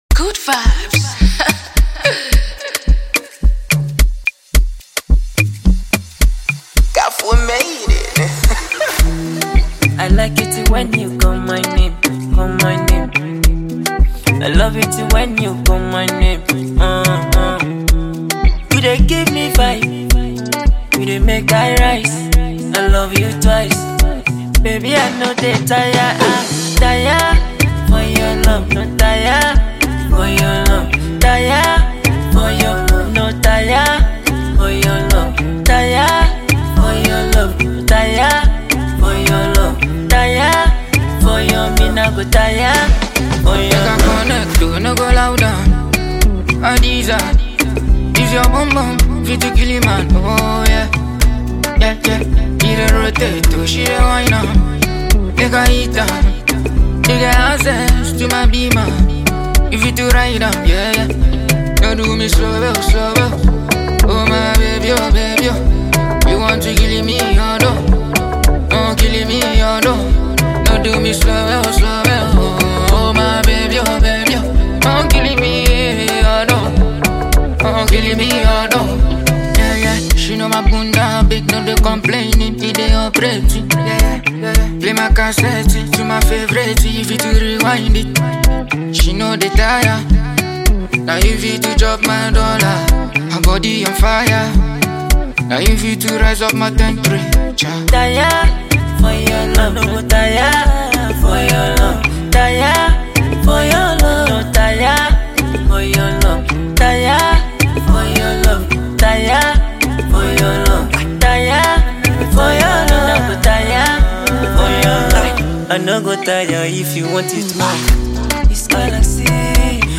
Ghanaian artist
featuring the talented music duo